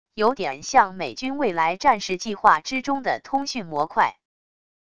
有点像美军未来战士计划之中的通讯模块wav音频